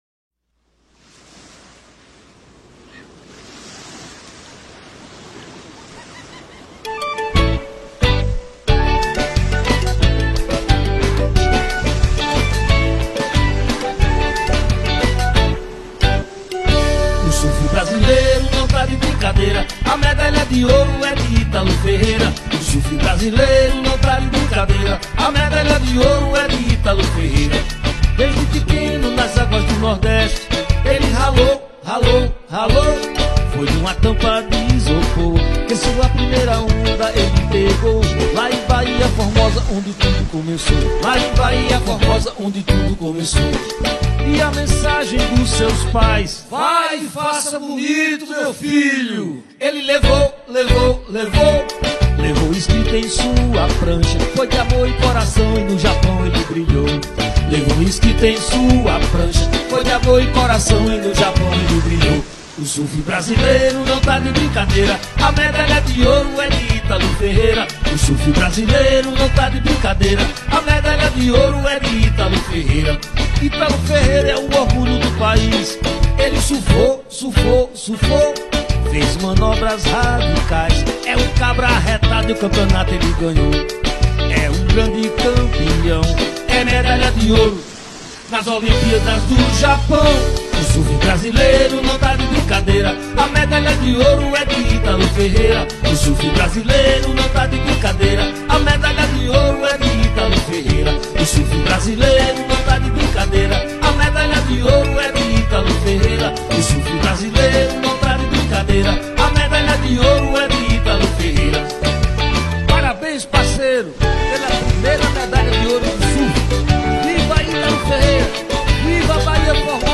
cantor e compositor